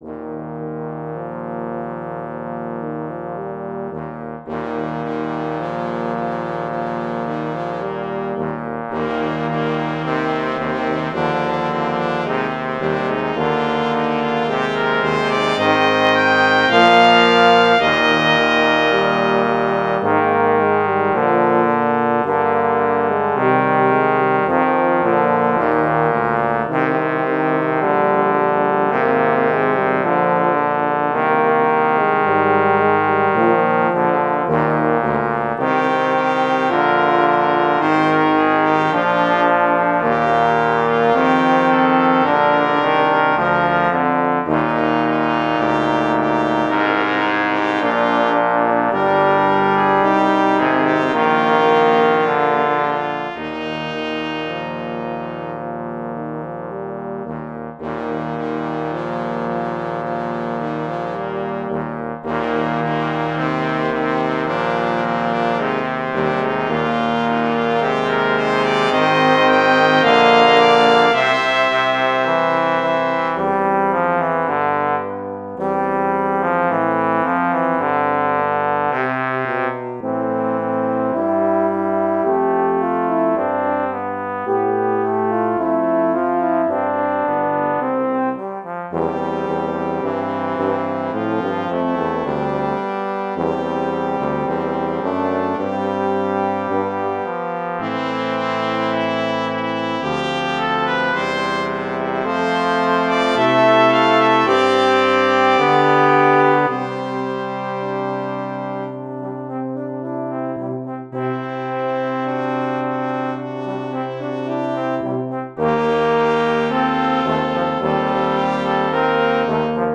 Brass quintet
Easter hymn arrangement